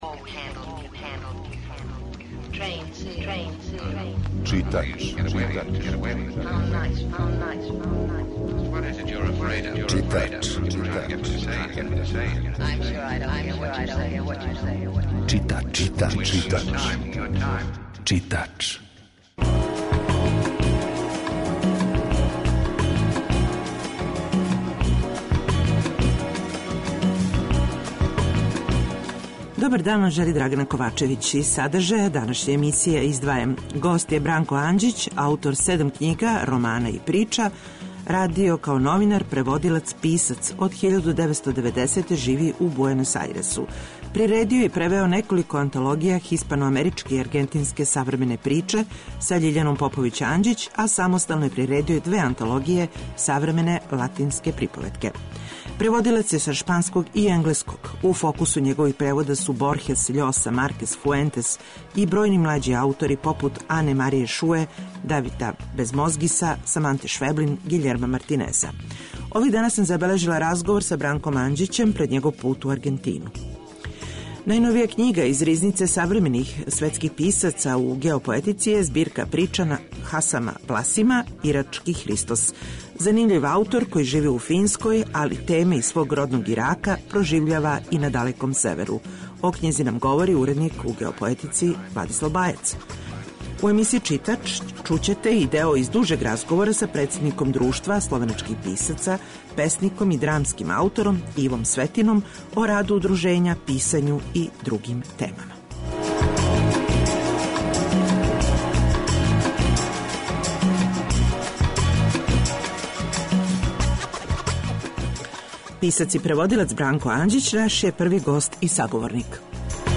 Емисија је колажног типа, али је њена основна концепција – прича о светској књижевности